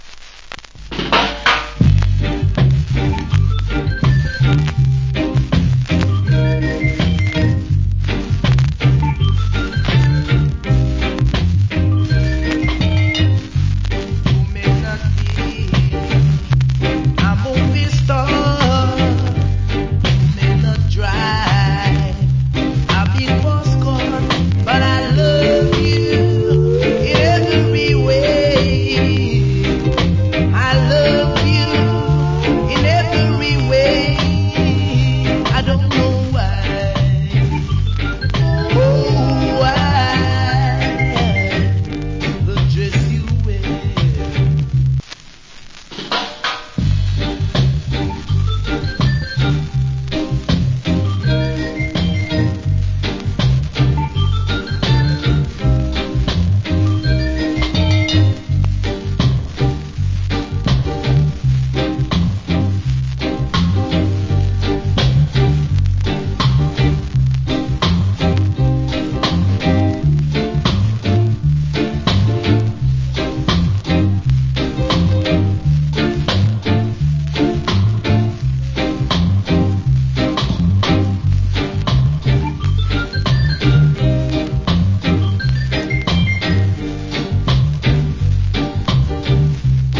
Cool Rock Steady.